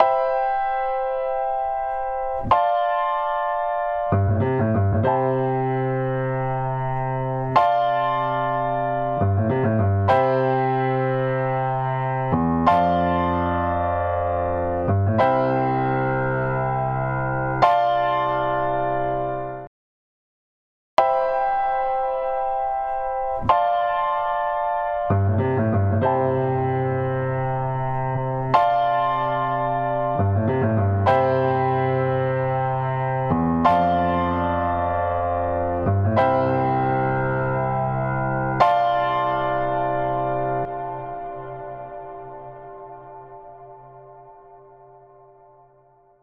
UltraReverb | Piano | Preset: Concert Hall
Piano-Concert-Hall.mp3